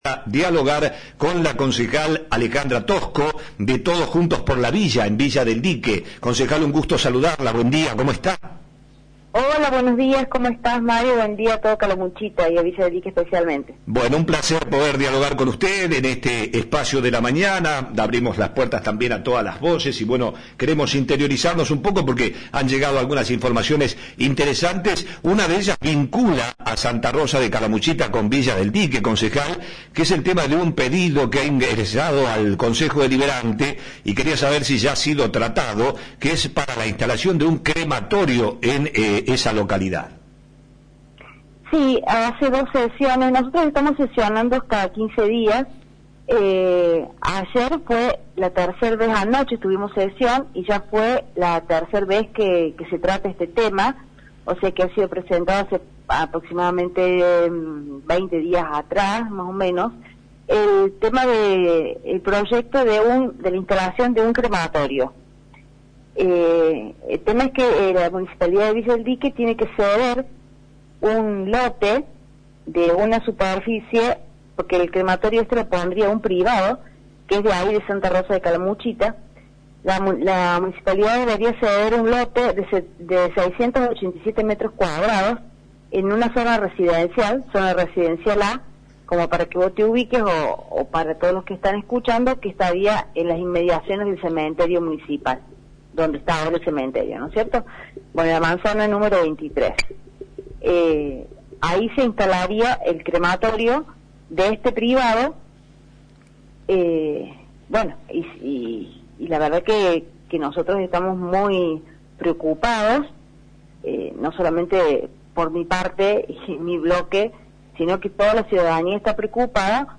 Lo confirmó en Flash FM la concejal de Todos Juntos por la Villa, Alejandra Tosco quien brindó todos los detalles.